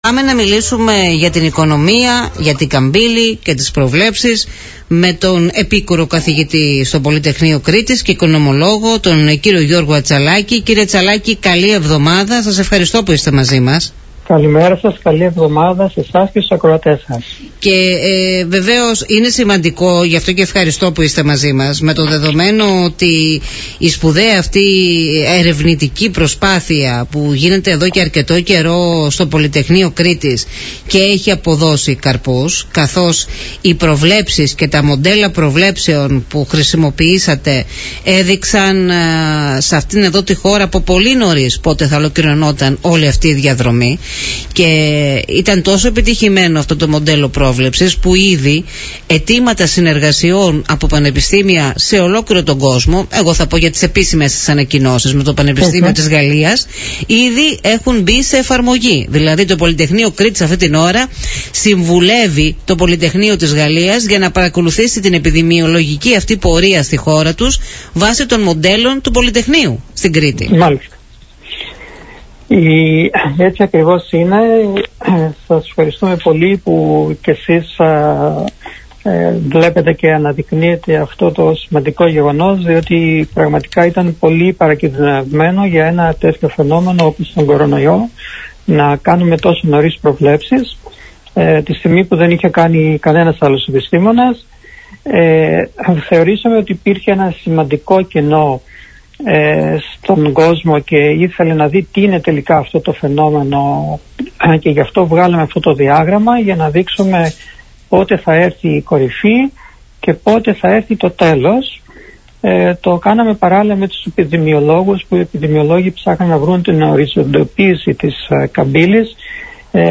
Για την επανεκκίνηση της οικονομίας, για τον τουρισμό αλλά και για τις χαμηλές τιμές του πετρελαίου, μίλησε στον Politica 89.8